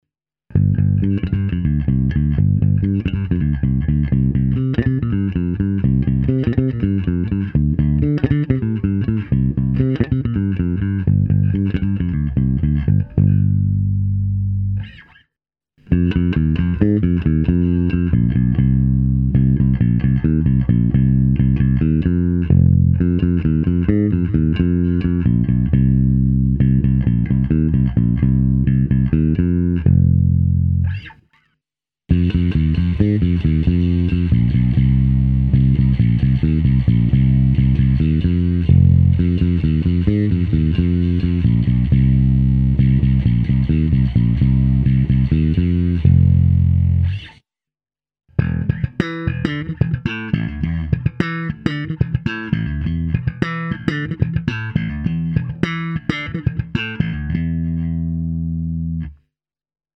Ukázka přes software AmpliTube se zapnutou simulací basového aparátu snímaného mikrofony, kde jsem zařadil i zkreslení.